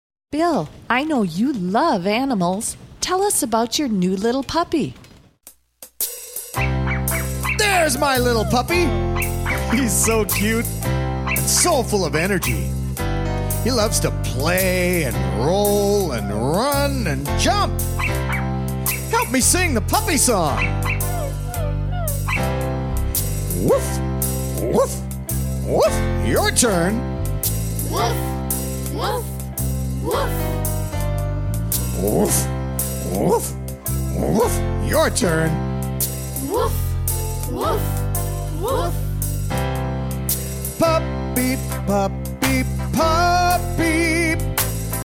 -Kids and adults singing together and taking verbal turns
Actions/puppy sounds